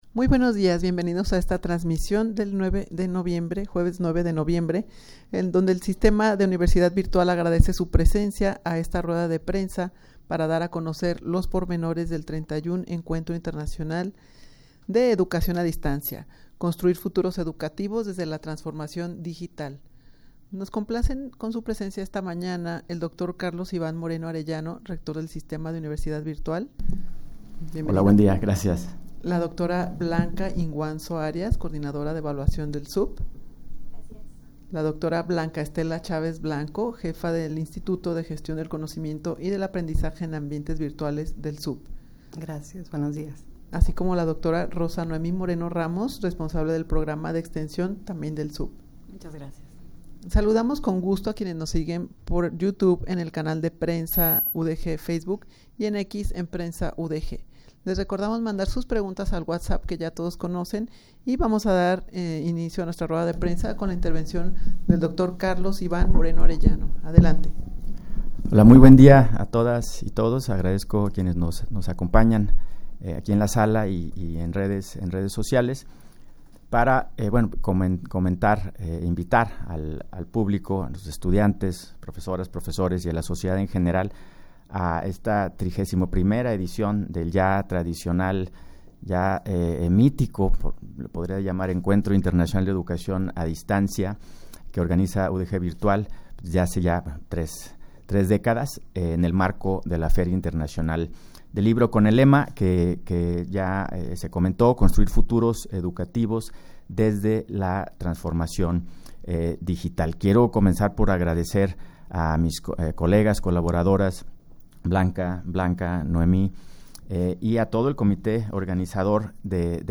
rueda-de-prensa-para-dar-a-conocer-los-pormenores-de-31-encuentro-internacional-de-educacion-a-distancia.mp3